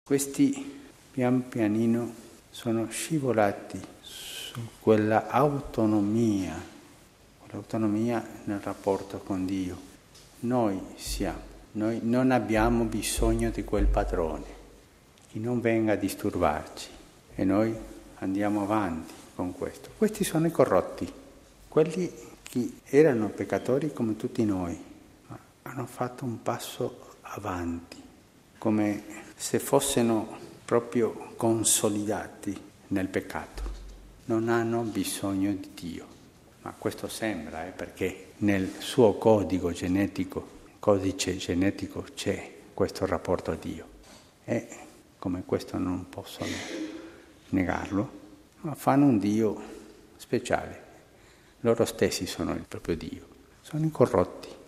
Påve Franciskus fokuserade på dessa tre i sin predikan vid mässan i Santa Martakapellet på måndagsmorgonen. Påven underströk att de korrupta gör Kyrkan mycket skada för att de dyrkar sig själva, medan helgonen däremot gör så mycket gott, de Kyrkans ljus.